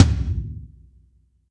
Tom 17.wav